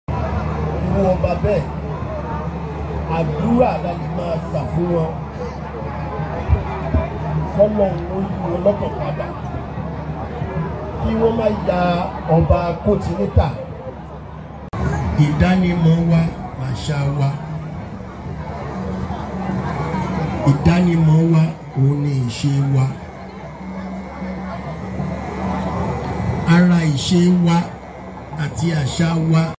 Scenes from 2025 Isese Festival